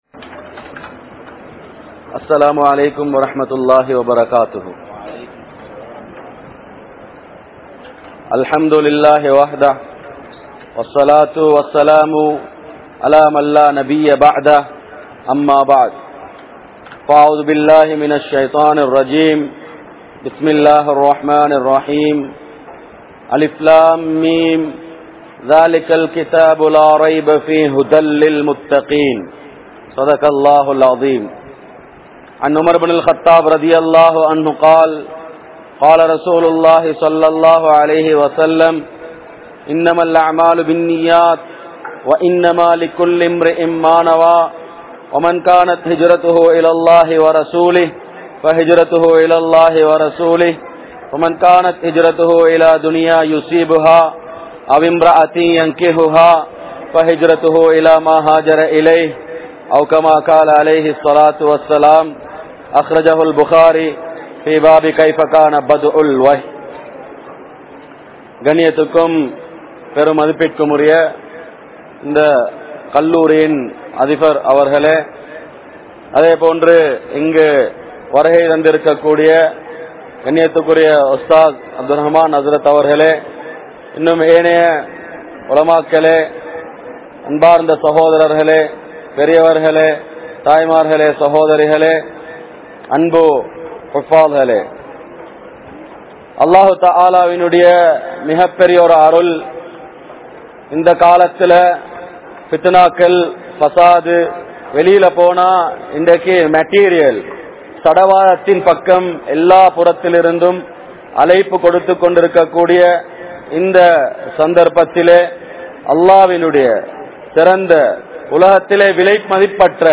Al Quran Koorum Atputhangal (அல்குர்ஆன் கூறும் அற்புதங்கள்) | Audio Bayans | All Ceylon Muslim Youth Community | Addalaichenai